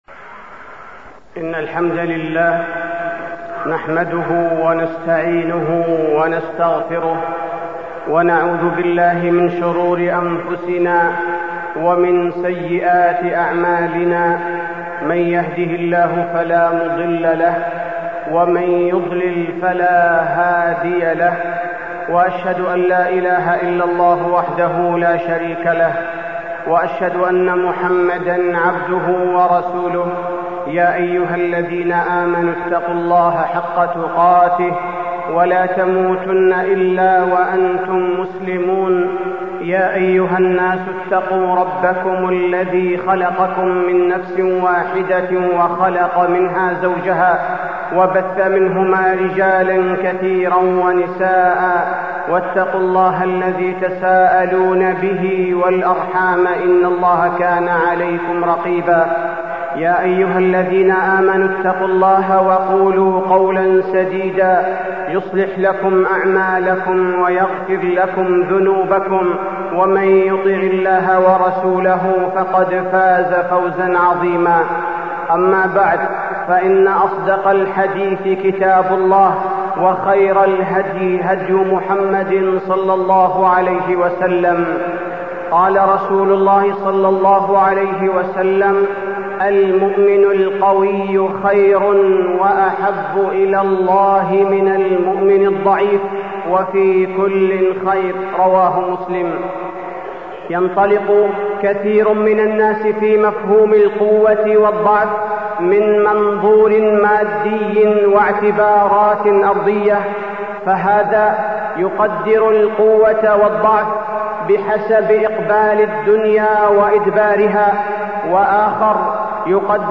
تاريخ النشر ١٣ شوال ١٤٢٥ هـ المكان: المسجد النبوي الشيخ: فضيلة الشيخ عبدالباري الثبيتي فضيلة الشيخ عبدالباري الثبيتي قوة العقيدة ورسوخ الإيمان